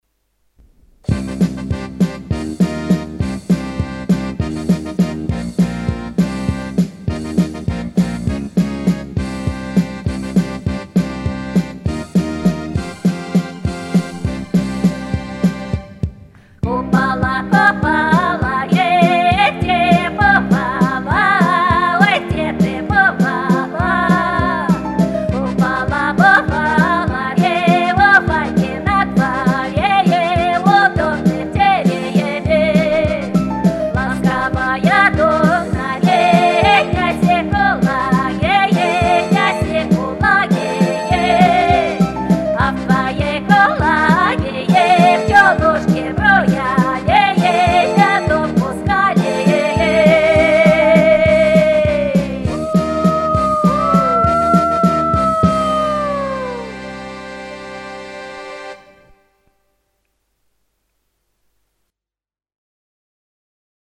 люблю этно музыку!)))
Народные- они и без муз. сопровождения хороши!!! Ещё более когда таким сочным голосом.